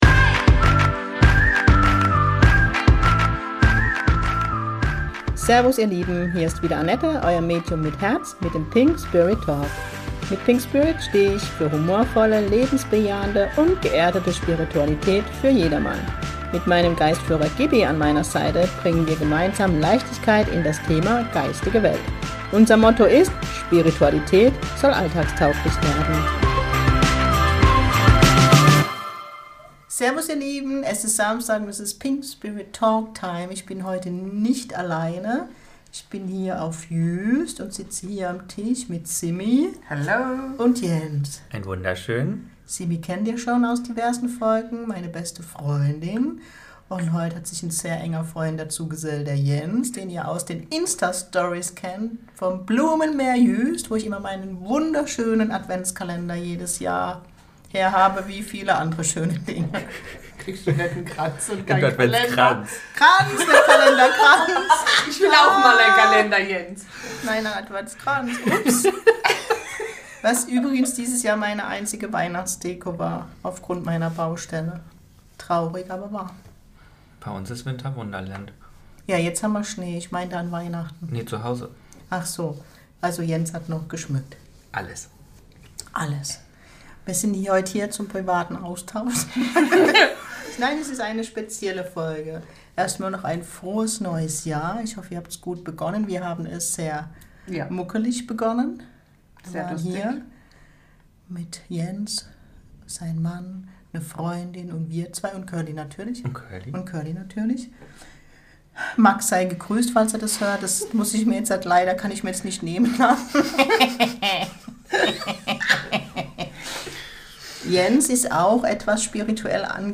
Es ist sehr privat und es ist so, wie wenn man mit den 3 live auf der Couch sitzt.